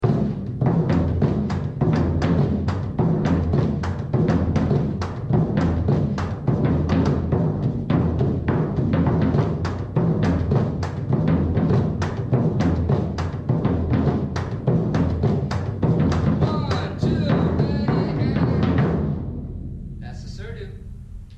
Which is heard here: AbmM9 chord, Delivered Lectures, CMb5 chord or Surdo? Surdo